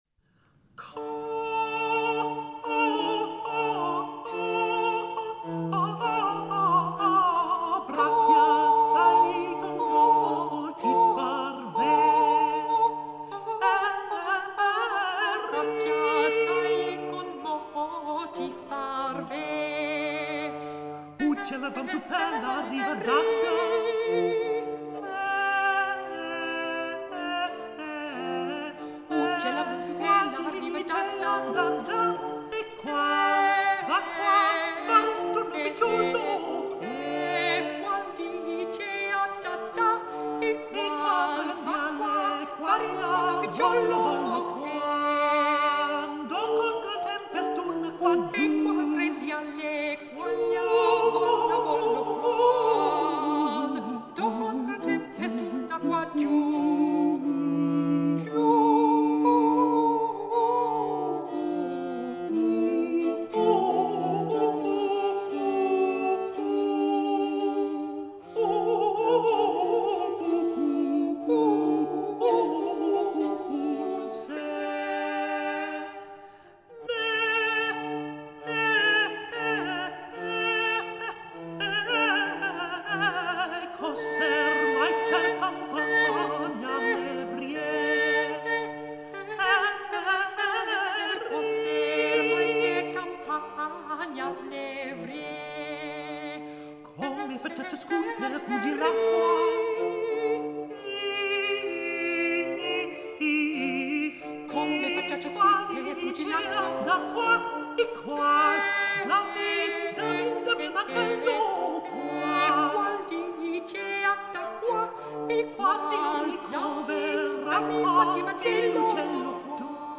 Con bracchi assai - Caccia